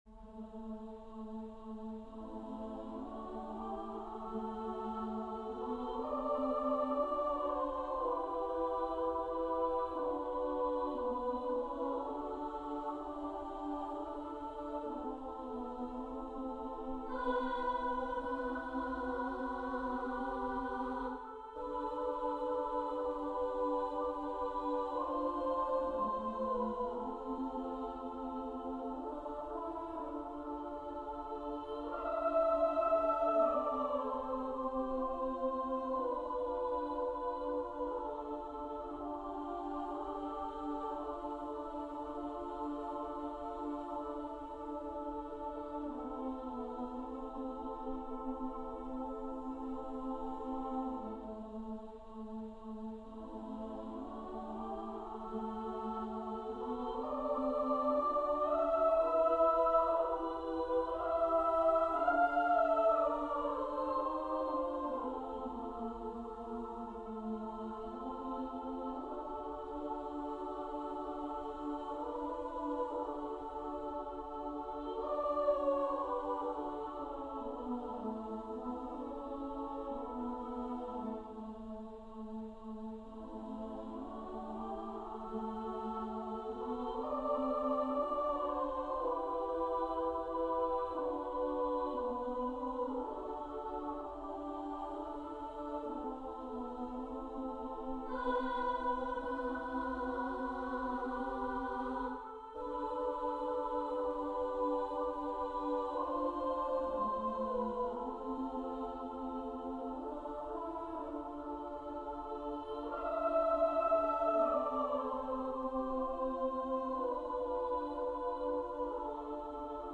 Emily Dickinson Number of voices: 3vv Voicings: SSA or TTB Genre: Secular, Anthem
Language: English Instruments: A cappella